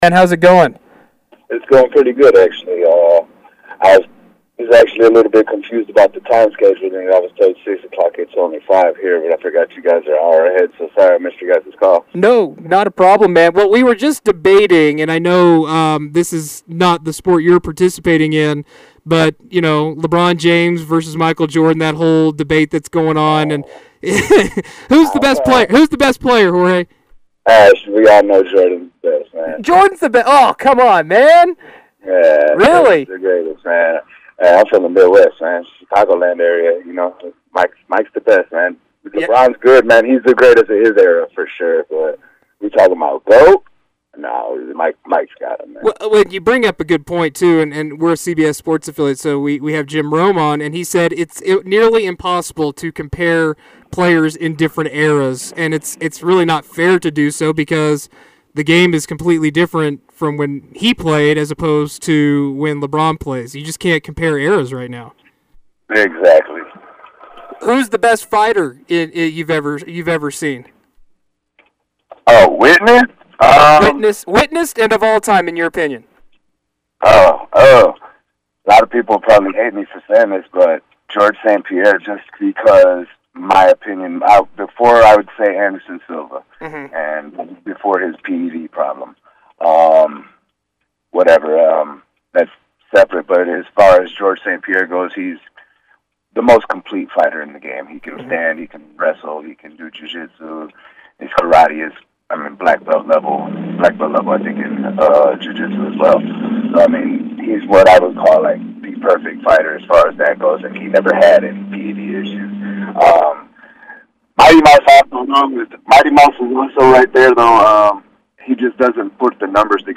He was on the SportsZone Tuesday afternoon.